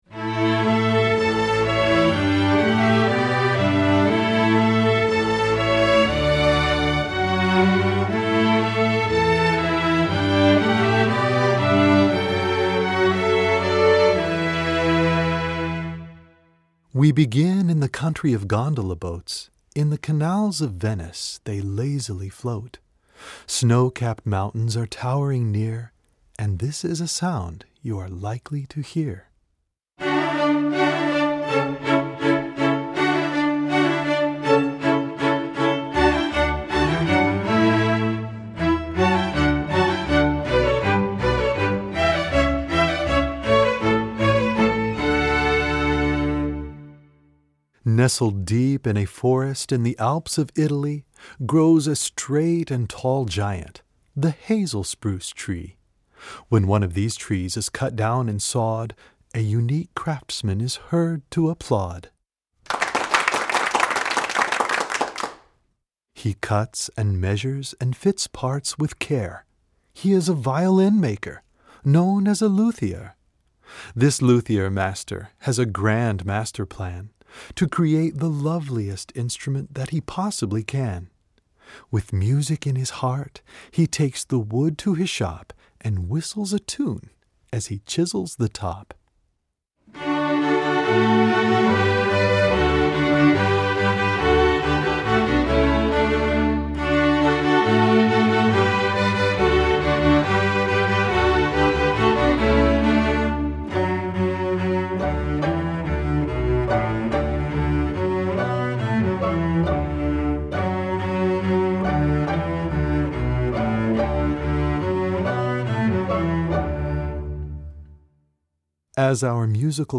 novelty